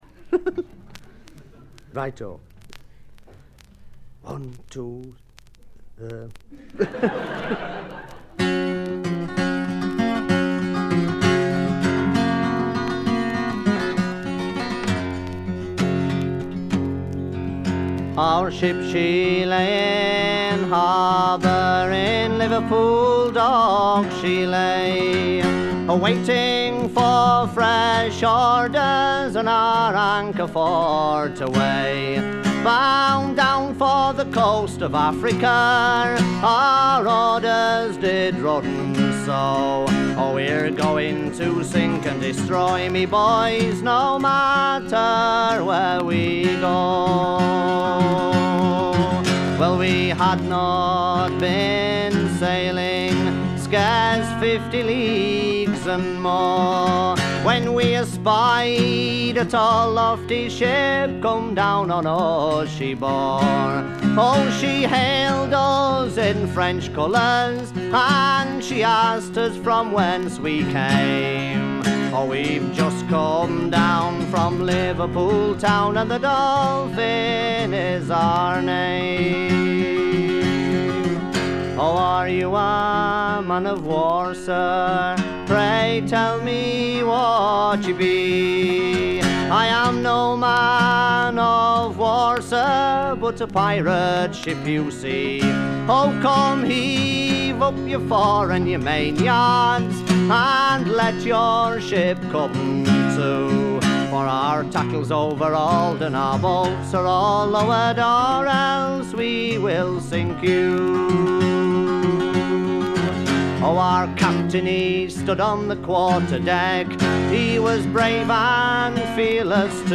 バックグラウンドノイズ、チリプチはそこそこ出ますが鑑賞を妨げるほどのノイズはありません。
自身のギターの弾き語りで全13曲。
試聴曲は現品からの取り込み音源です。
guitar, vocals